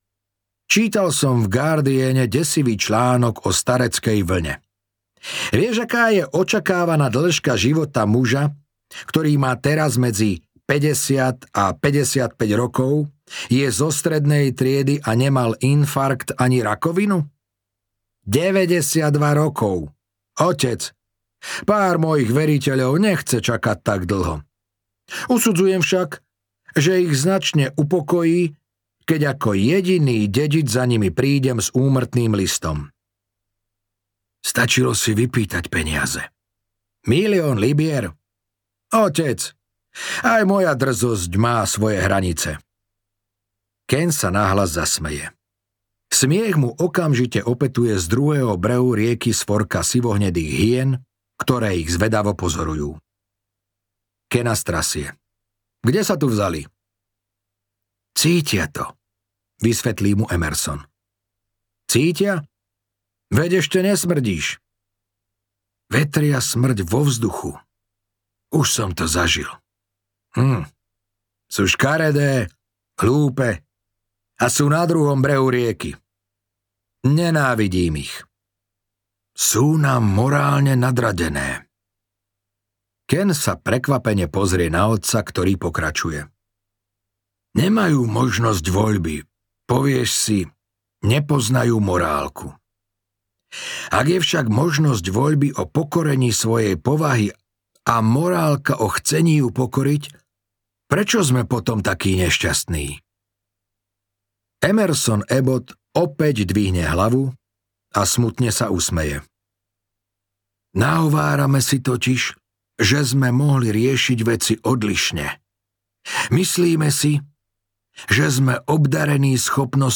Ostrov potkanov a iné príbehy audiokniha
Ukázka z knihy